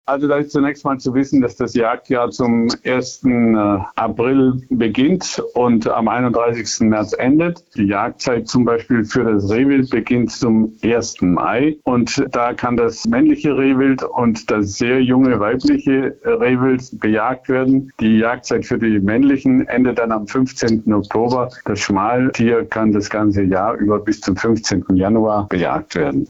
Interview: Aufgaben eines Jägers - PRIMATON